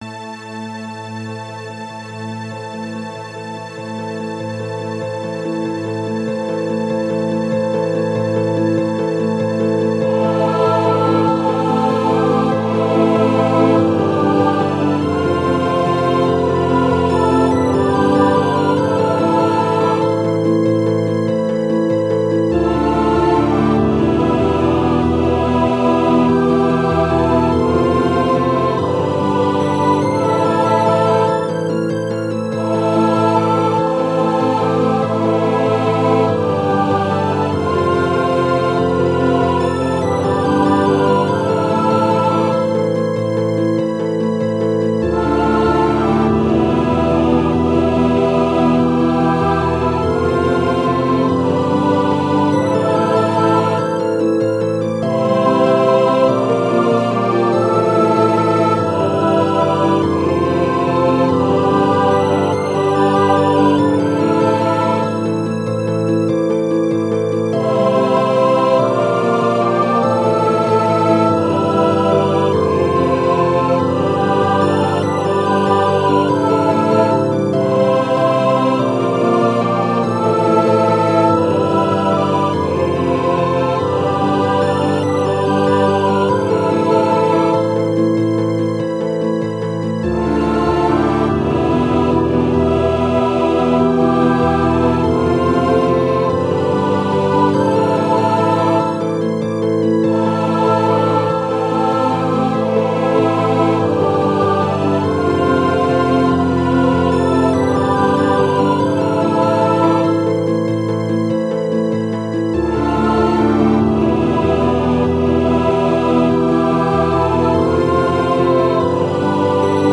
Voicing/Instrumentation: SATB , Organ/Organ Accompaniment